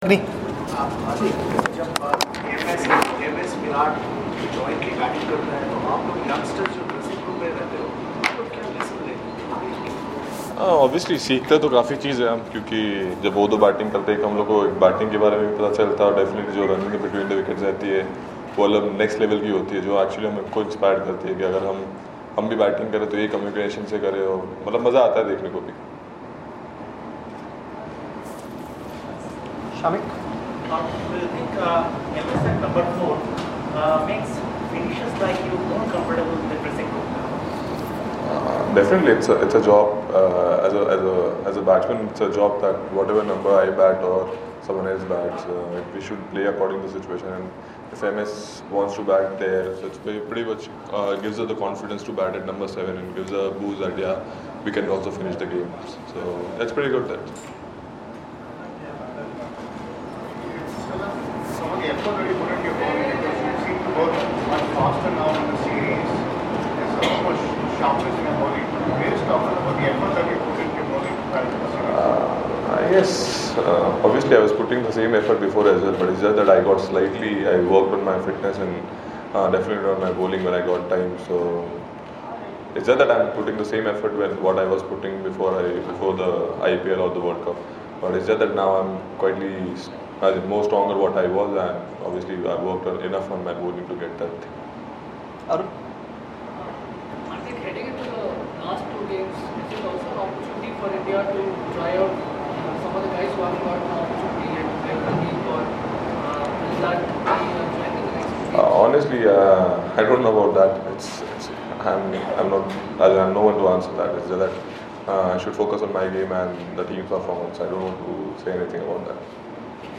Hardik Pandya speaks on the eve of India's 4th ODI against New Zealand at Dhoni's home ground.